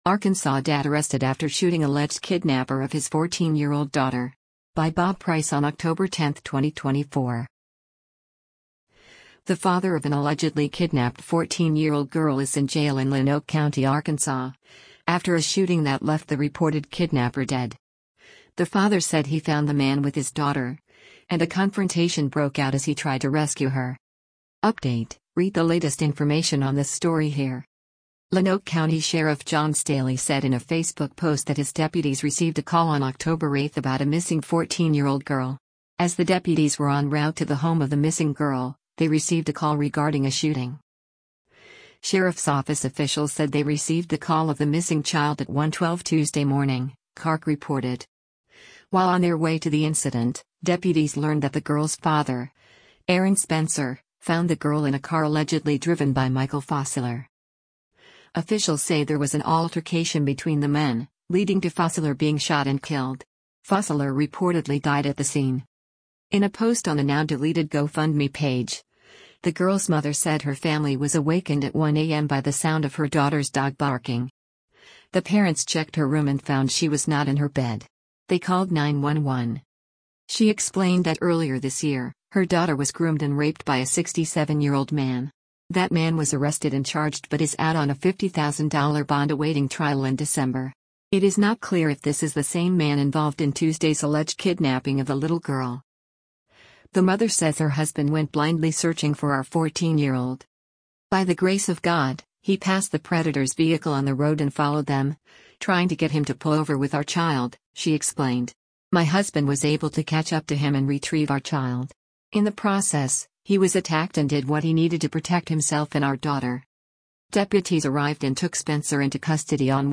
“This is a tragic situation, and my thoughts and prayers are with all those involved,” Sheriff Staley said in his video statement.